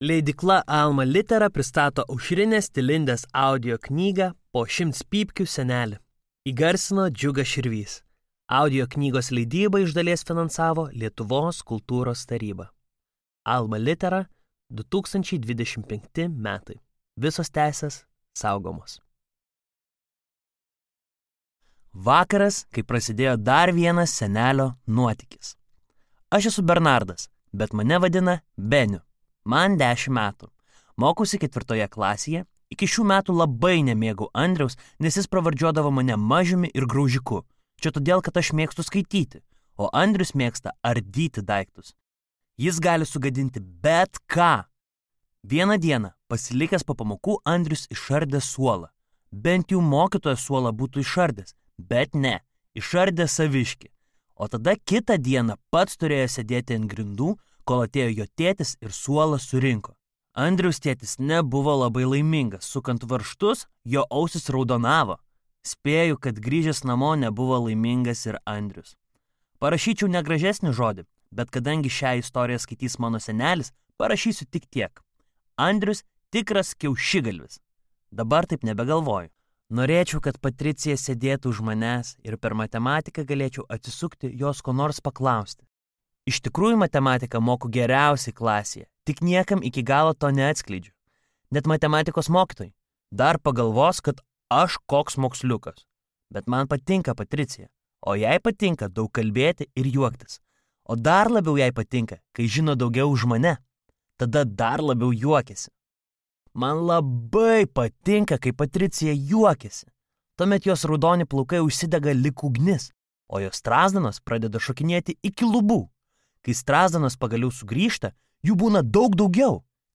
Audioknygos leidybą iš dalies finansavo Lietuvos kultūros taryba.